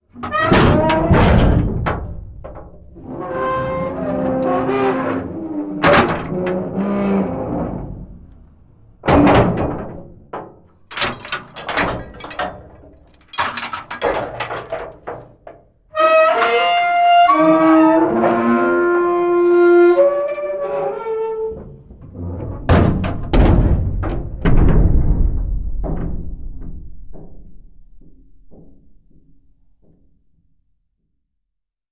音效-43个大型门重型门打开无损音效-学驰资源
音效介绍43个大型门重型门打开无损音效，它本身的声音听起来很沉重，可试听，Desiqninq大型门音效，适用于令人毛骨悚然的气氛。